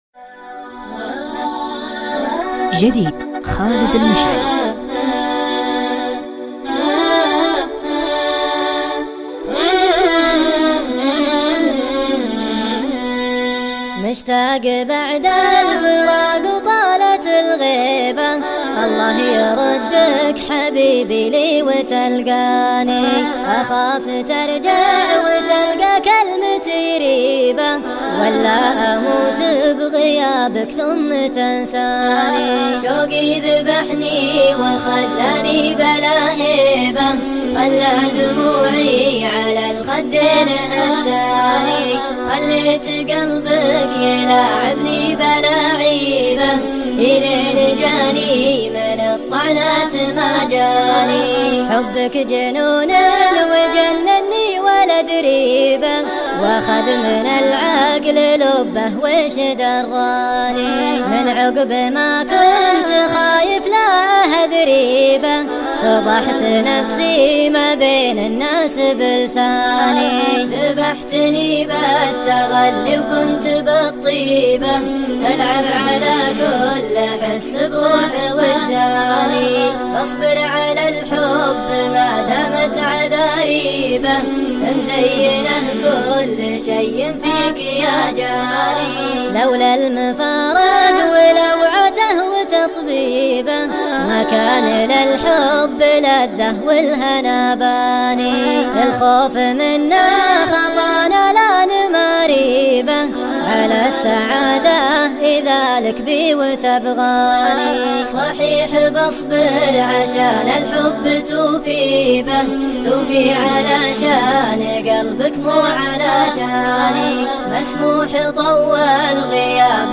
شيلة
مسرع